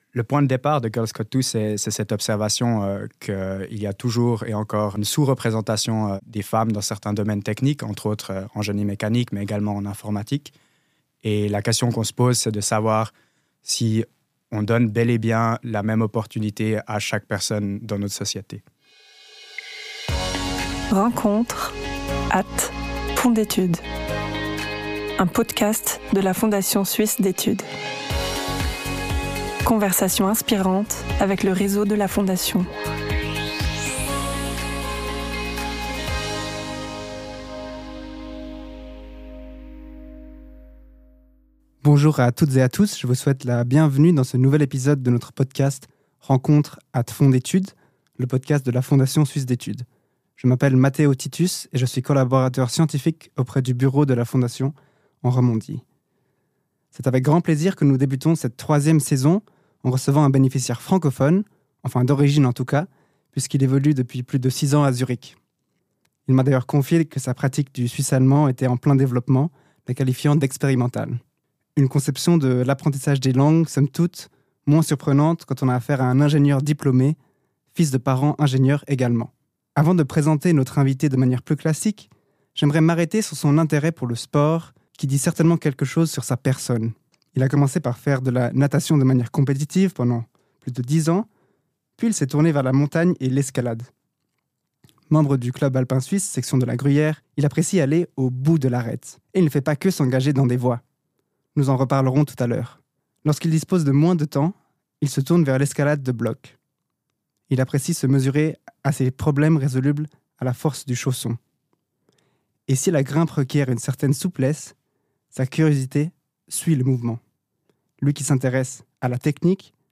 Une conversation autour des richesses de l’interdisciplinarité, de la détermination et de l’engagement.